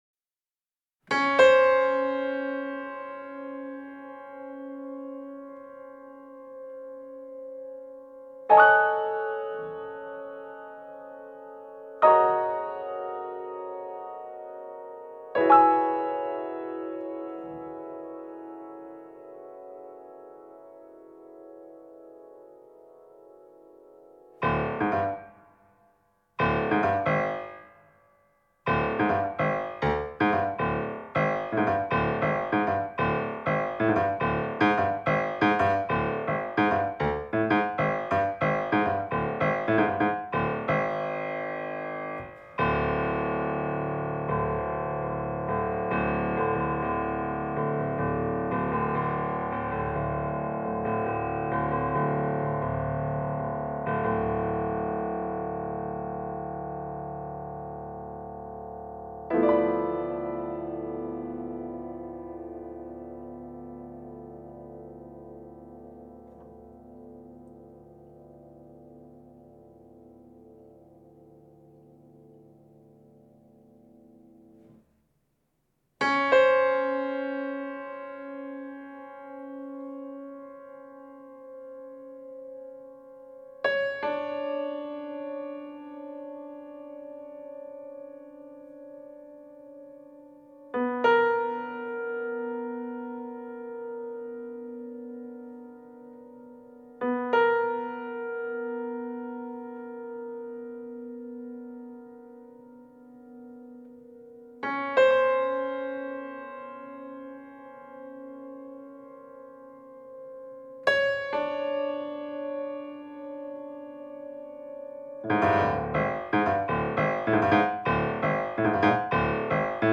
free jazz and improvised music
piano